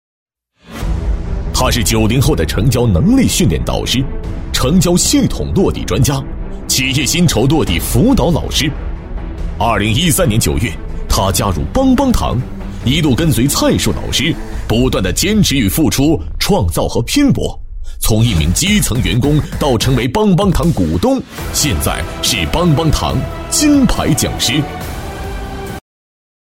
电视购物配音